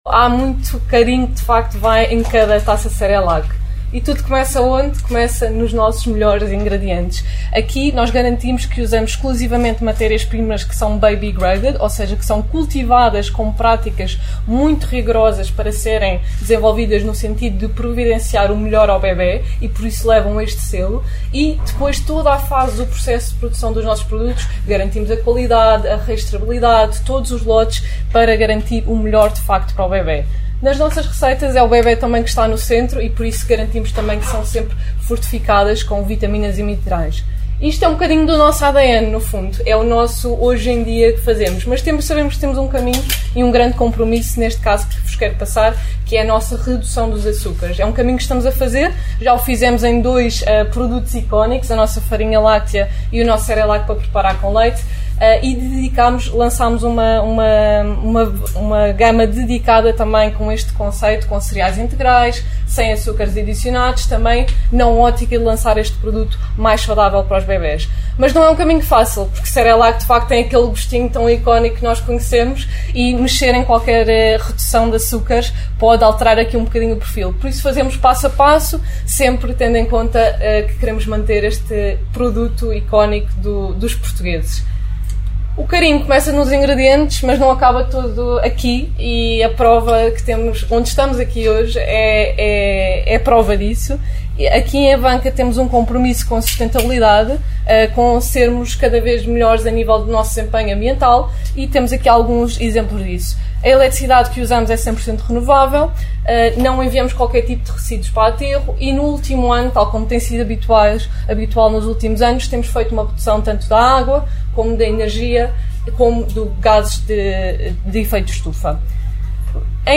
Após estas introduções, que pode ouvir na integra clicando nos players respetivos, a organização das celebrações levou os convidados a visitar parte das instalações fabris, num esforço de bem receber revestido de uma logística complexa, no intuito de garantir a segurança máxima, apanágio de toda a organização.